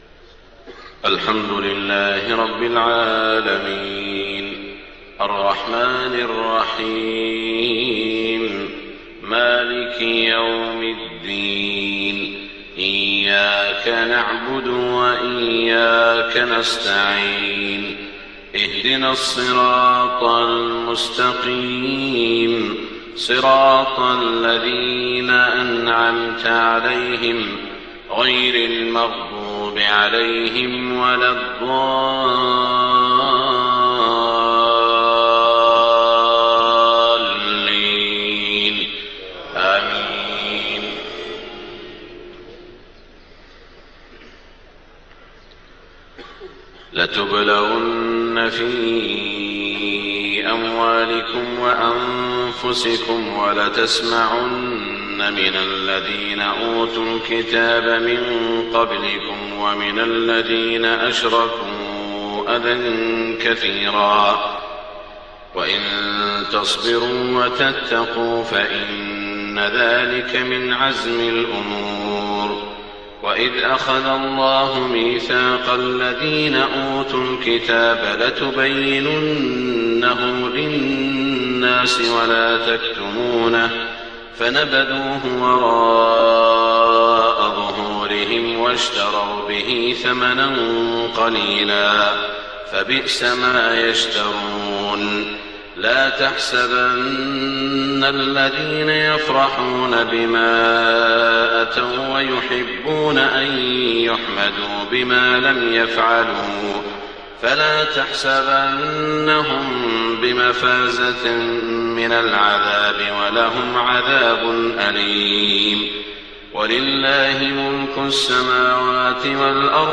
صلاة الفجر 25 ذو الحجة 1427هـ من سورة آل عمران > 1427 🕋 > الفروض - تلاوات الحرمين